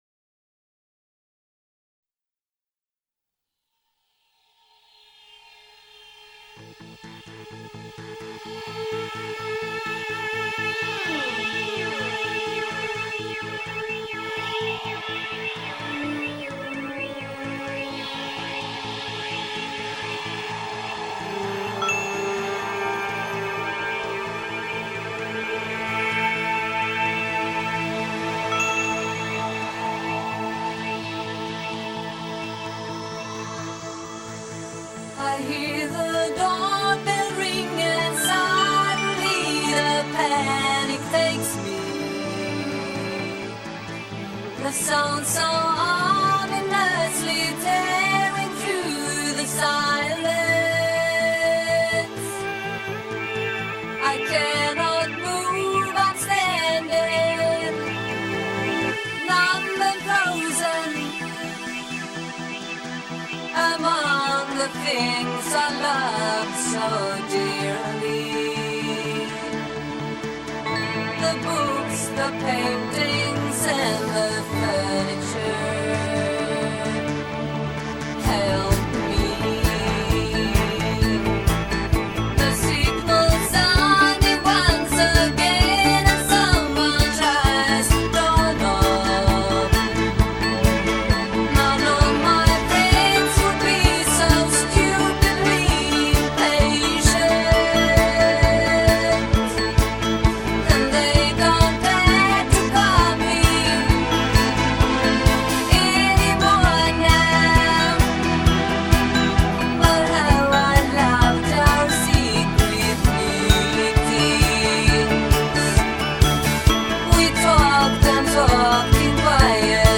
Pop, Synth-Pop